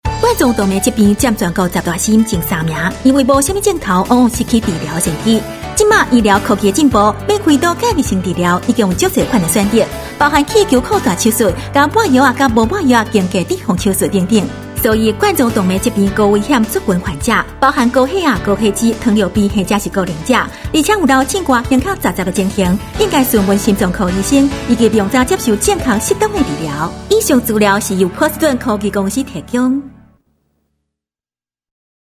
台語配音 國語配音 女性配音員